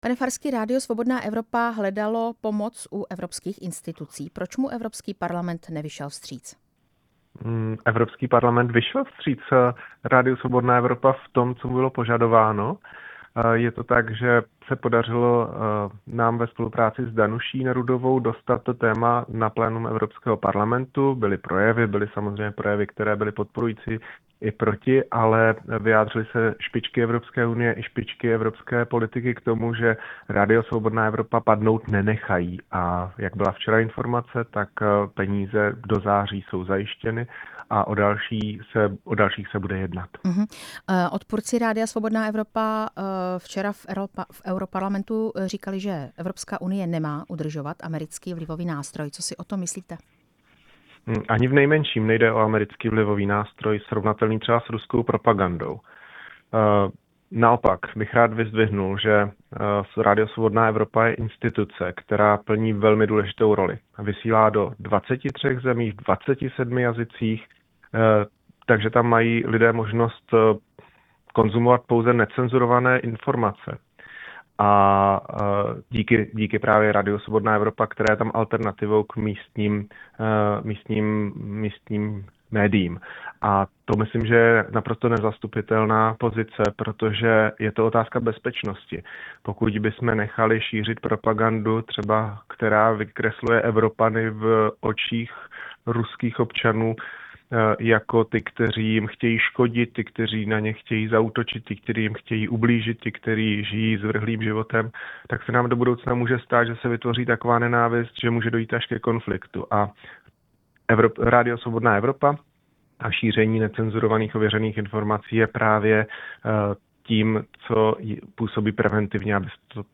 Ve vysílání Radia Prostor jsme téma Svobodné Evropy probírali s evropským poslancem Janem Farským z hnutí STAN.
Rozhovor s s evropským poslancem Janem Farským z hnutí STAN